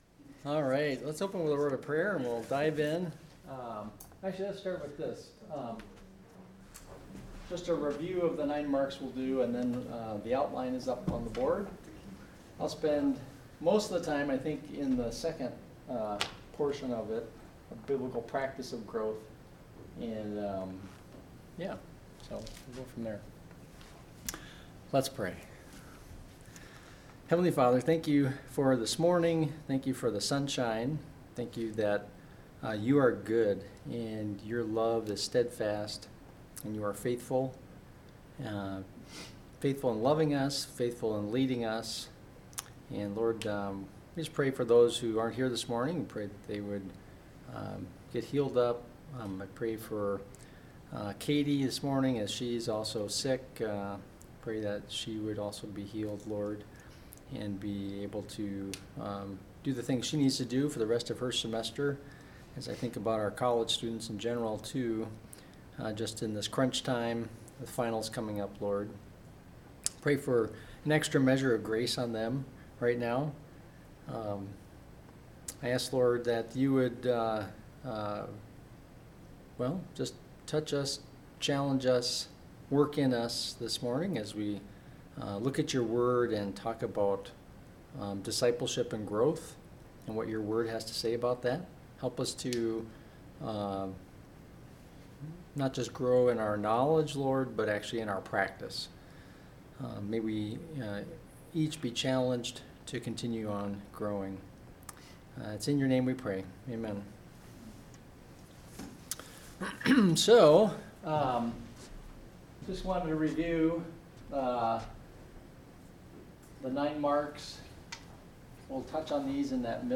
Tags: Sunday school